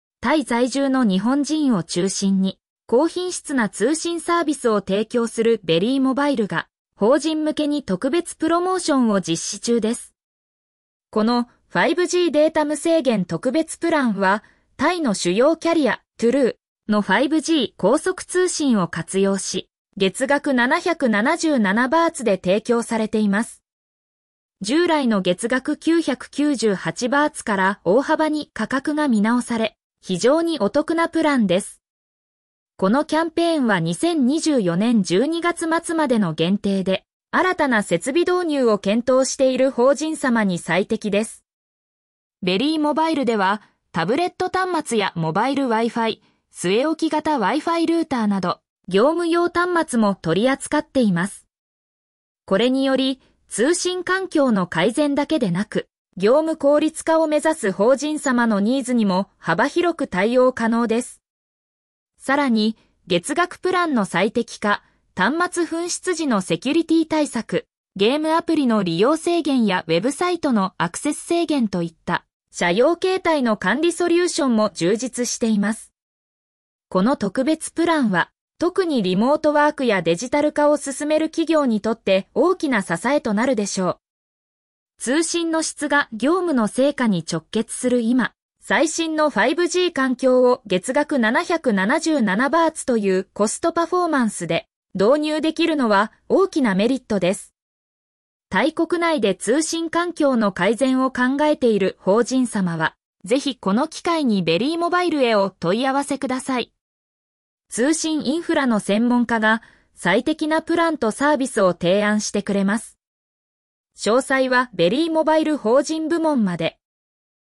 読み上げ タイ在住の日本人を中心に、高品質な通信サービスを提供するベリーモバイルが、法人向けに特別プロモーションを実施中です。